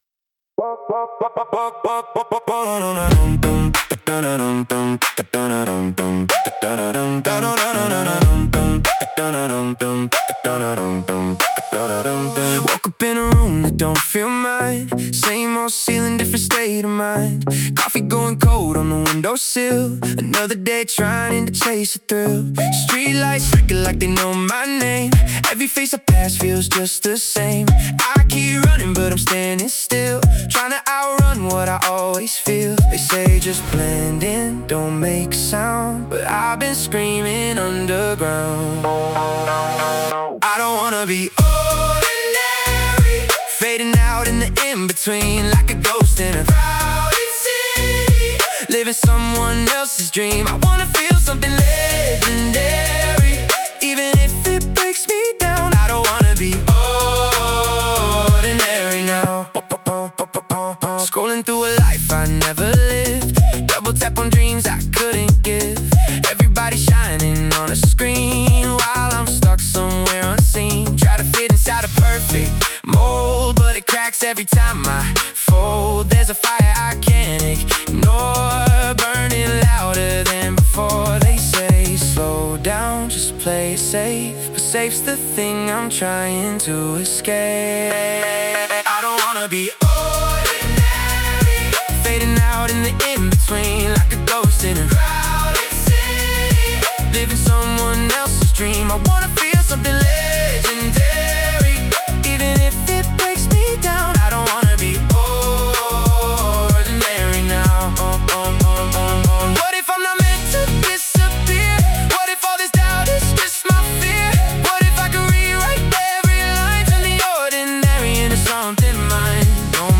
Pop 2026 Non-Explicit